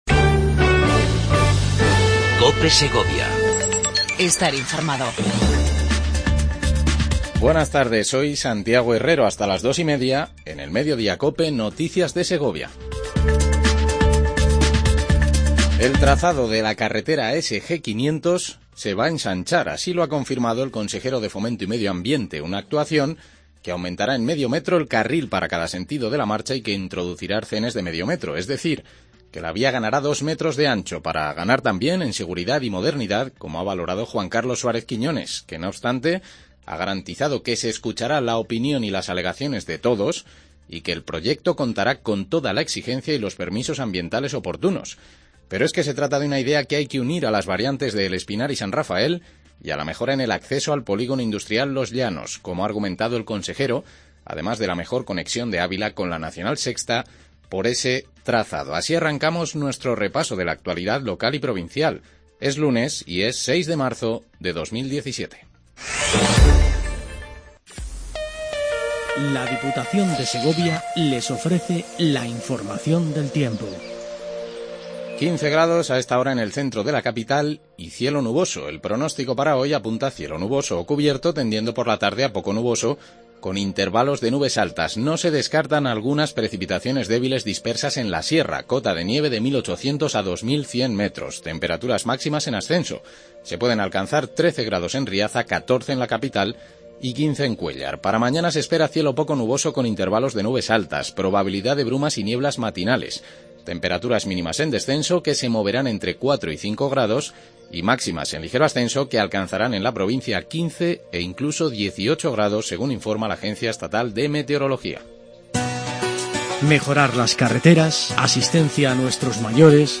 INFORMATIVO MEDIODIA COPE EN SEGOVIA